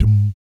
Index of /90_sSampleCDs/ILIO - Vocal Planet VOL-3 - Jazz & FX/Partition B/4 BASS THUMS